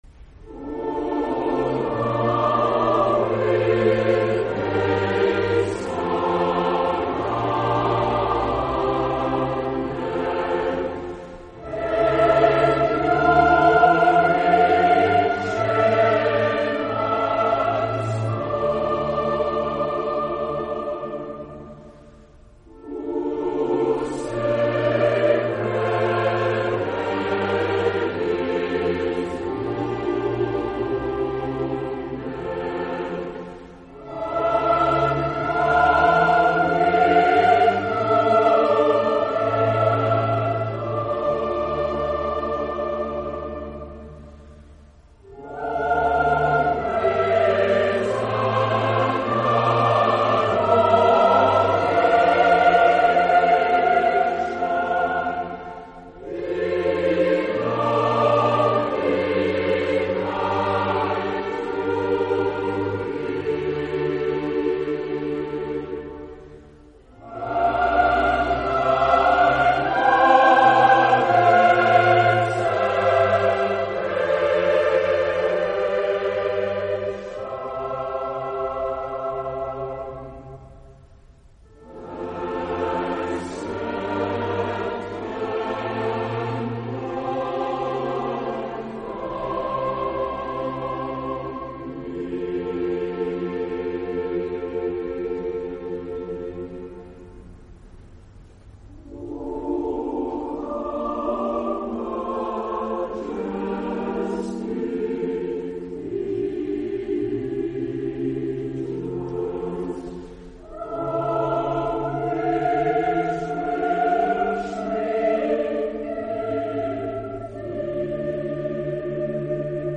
“O Thou with Hate Surrounded Chorale (BWV 44, No. 63)” from St. Matthew’s Passion.
Performed by Leonard Bernstein (conductor) and the Collegiate Chorale and Boys' Choir of the Church of the Transfiguration.